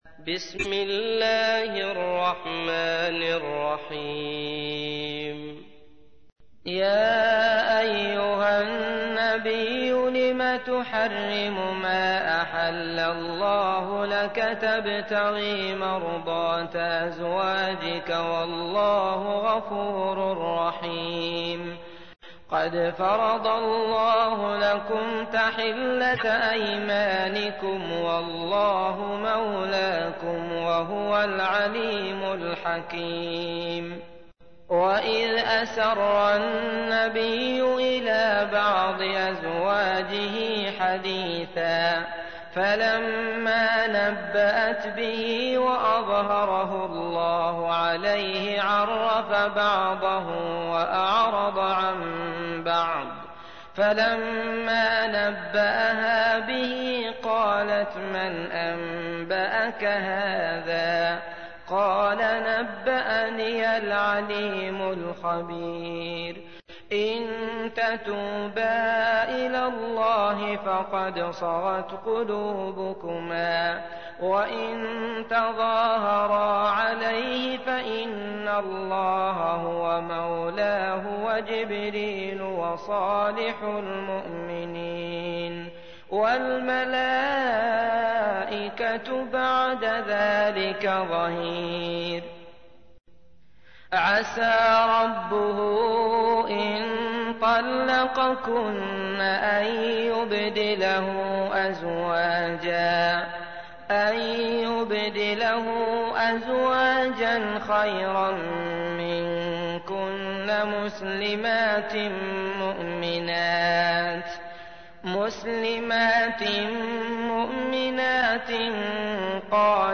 تحميل : 66. سورة التحريم / القارئ عبد الله المطرود / القرآن الكريم / موقع يا حسين